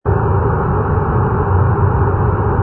exhaust vent.wav